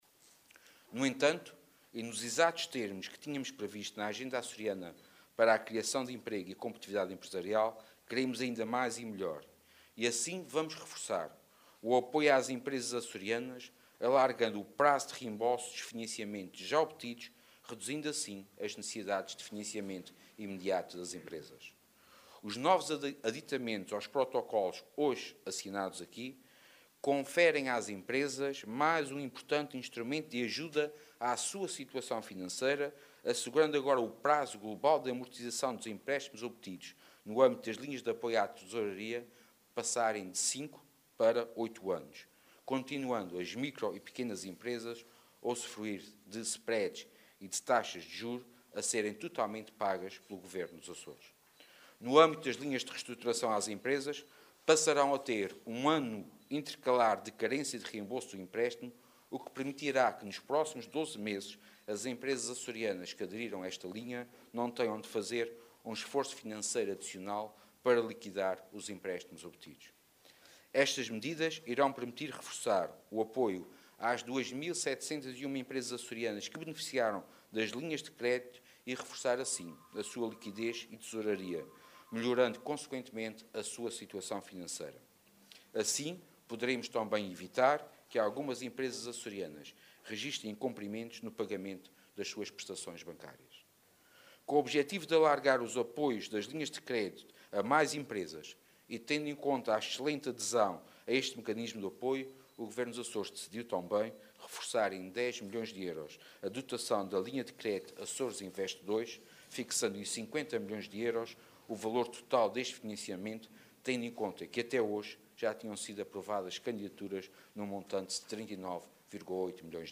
Sérgio Ávila que falava na cerimónia de assinatura dos protocolos das linhas de crédito de apoio às empresas com as instituições bancárias, reafirmou o empenho do Governo Regional, através da introdução de alterações aos programas de financiamento às empresas.